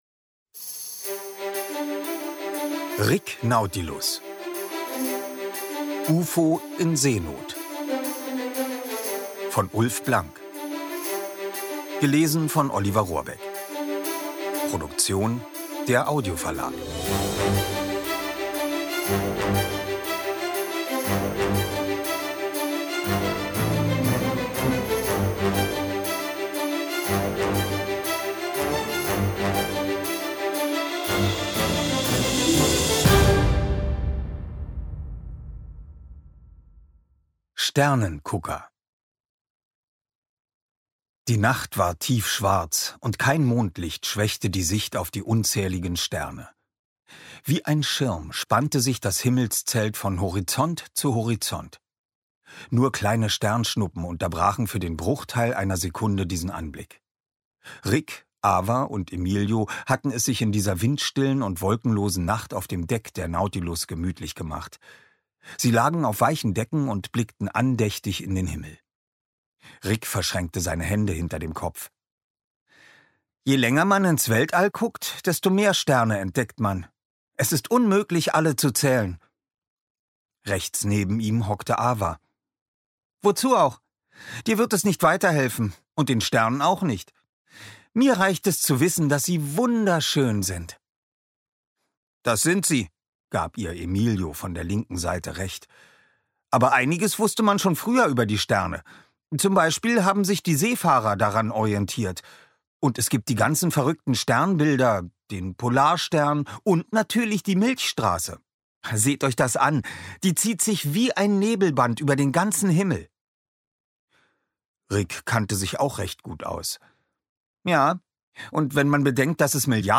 Rick Nautilus – Teil 5: Ufo in Seenot Ungekürzte Lesung mit Musik mit Oliver Rohrbeck
Oliver Rohrbeck (Sprecher)